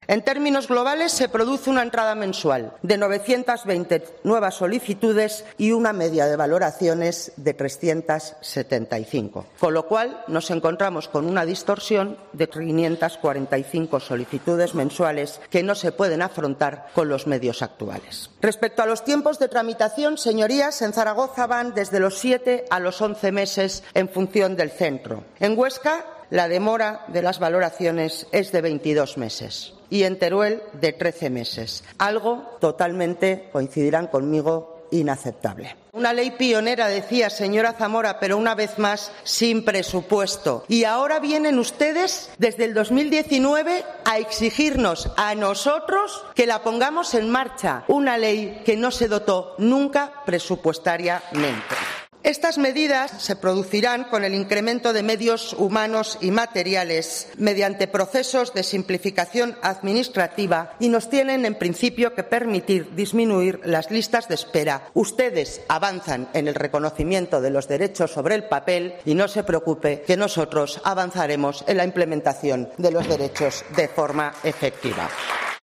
La consejera Carmen Susín explica las listas de espera en discapacidad y promete más medios